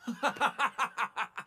Greed Laughing.wav